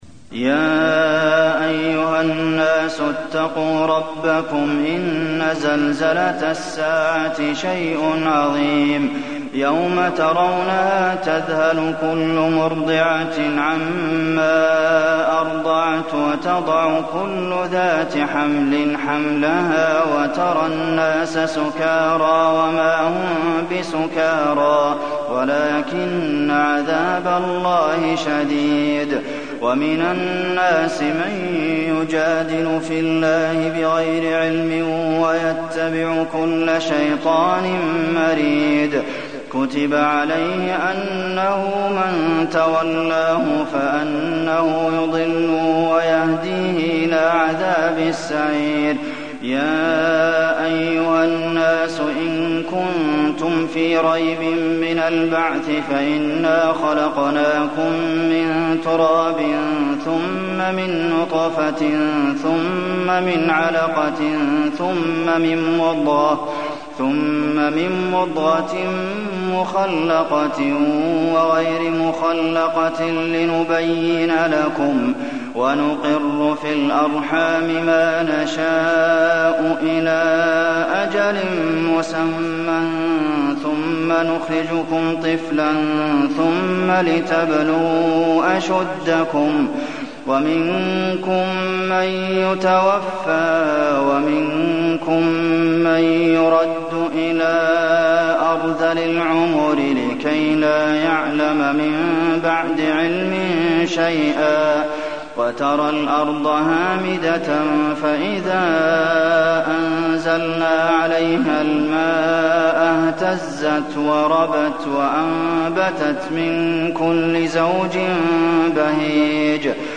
المكان: المسجد النبوي الحج